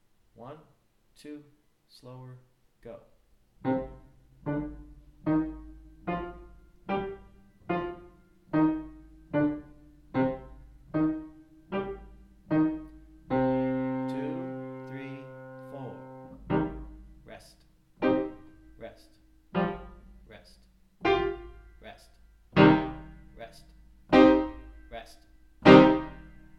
PIANO TIPS - WARM-UP EXERCISES
c minor (qn=60,
qn=77 (with dynamics), qn=80, qn=100, qn=120)